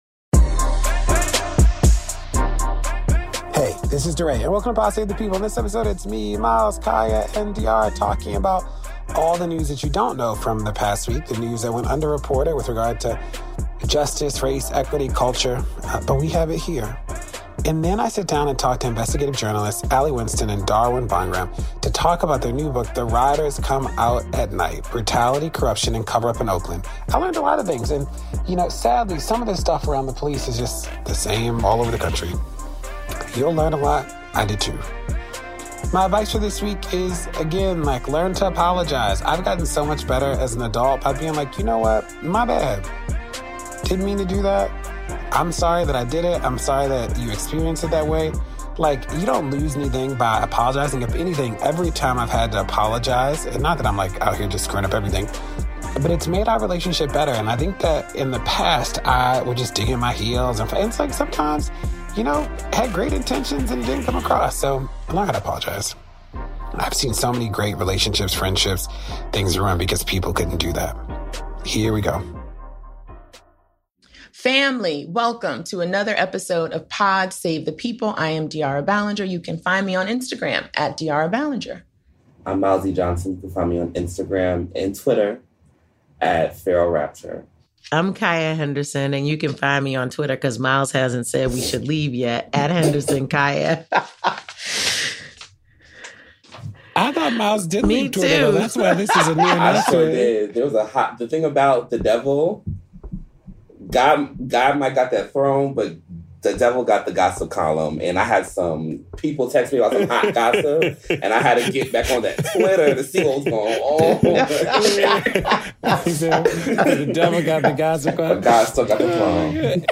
DeRay interviews investigative reporters